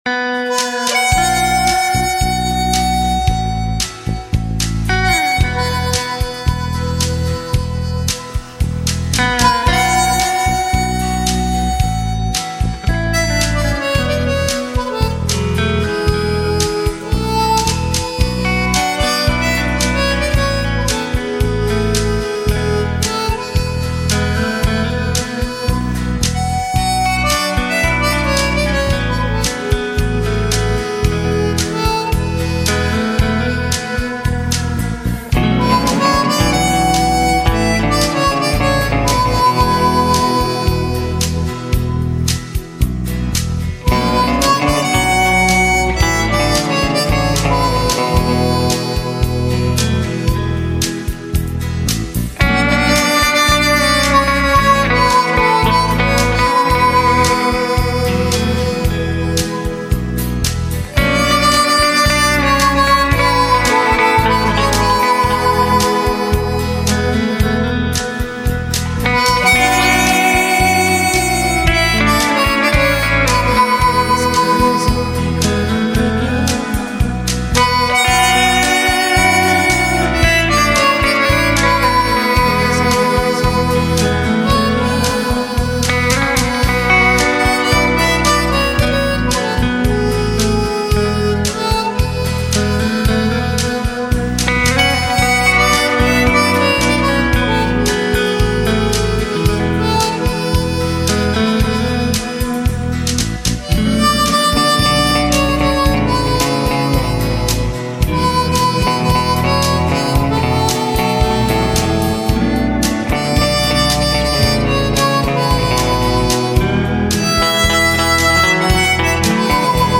Golden Oldies - Classic Hits  - Sing a Long Songs